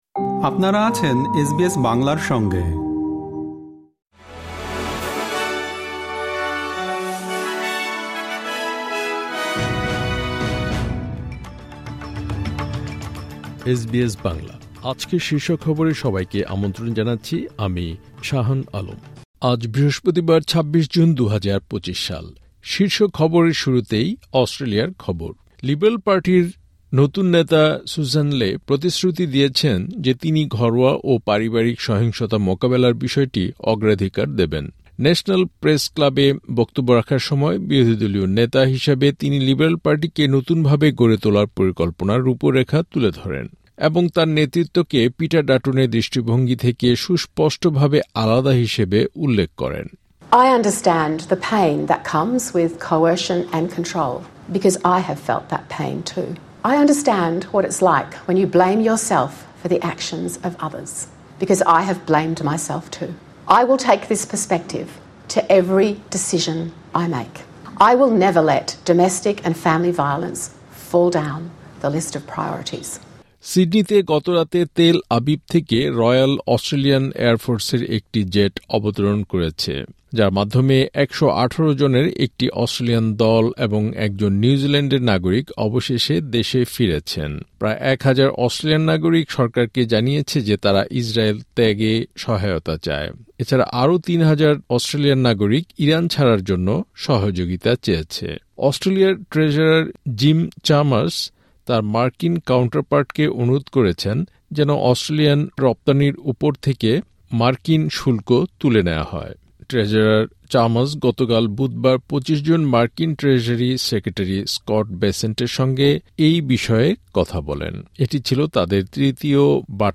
এসবিএস বাংলা শীর্ষ খবর: ২৬ জুন, ২০২৫